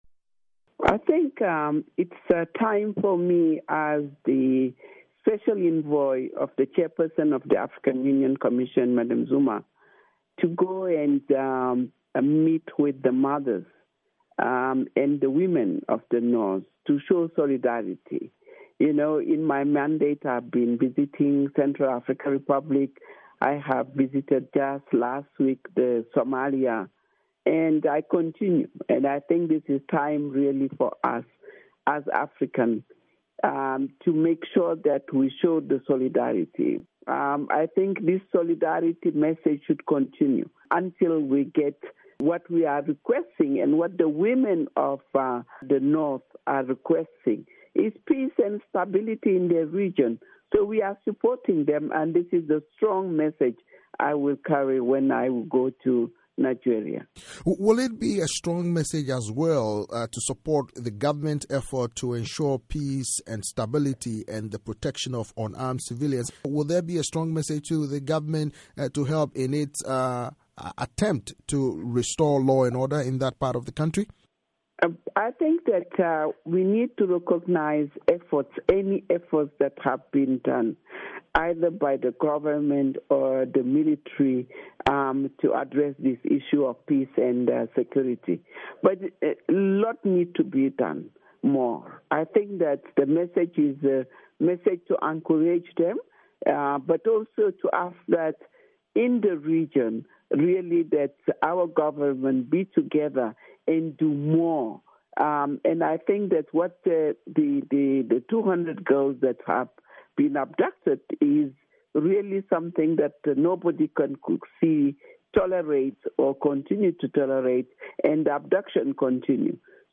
In an interview with VOA, Bineta Diop says one of the objectives of her trip is to echo the voices of the women and support their efforts to bring an end to the ordeal of the Chibok girls and other forms of gender-based violence.